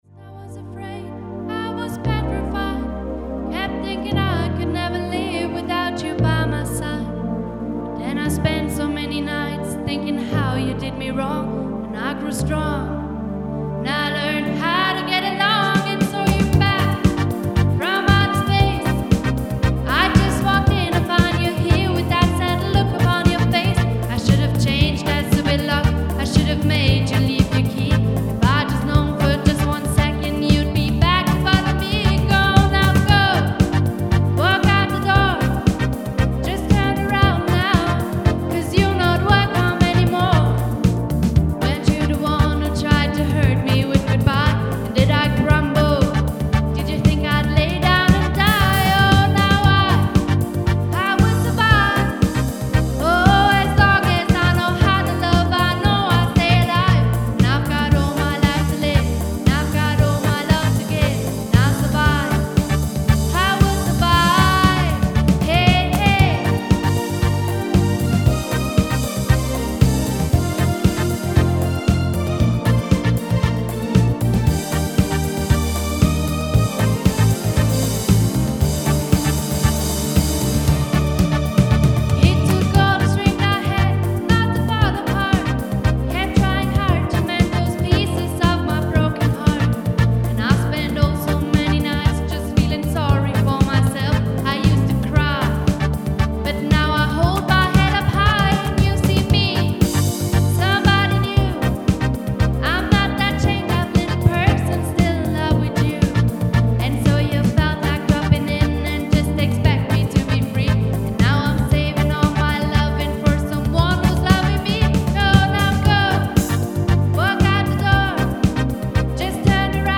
Kulthits, Austropop & alte Hadern